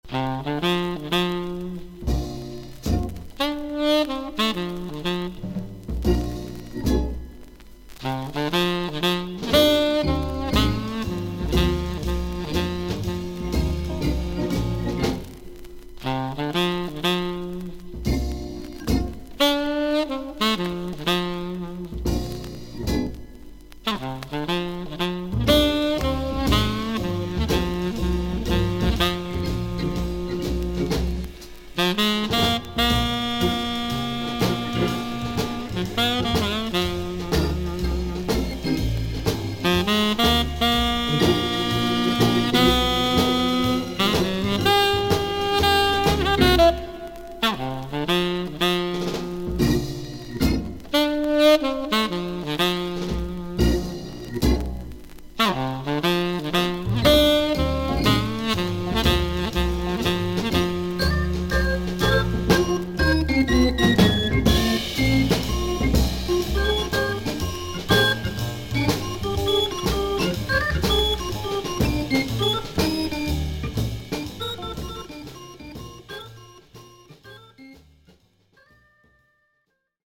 少々軽いパチノイズの箇所あり。少々サーフィス・ノイズあり。クリアな音です。
ジャズ・テナー・サックス奏者。
オルガンをフィーチャーしたアーシーなサウンドです。